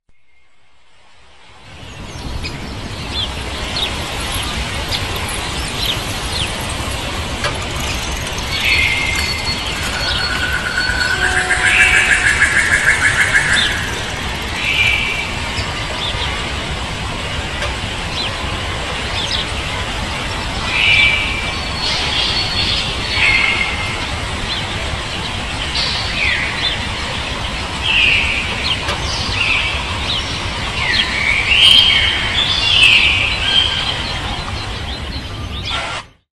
Sonidos de fondo bosque tropical 1, efectos de sonido, descargar en mp3 gratis
Los sonidos de fondo de un bosque tropical ofrecen una inmersión auditiva en uno de los ecosistemas más ricos y vibrantes del planeta. Estos sonidos son una mezcla compleja de elementos naturales que crean una atmósfera única y envolvente. Desde el susurro de las hojas movidas por la brisa hasta el canto de una miríada de aves exóticas, cada sonido contribuye a la sinfonía del bosque.
La alta calidad de las grabaciones permite capturar la riqueza y la profundidad de los sonidos del bosque, asegurando una experiencia auditiva auténtica.
Sonidos de fondo bosque tropical 1.mp3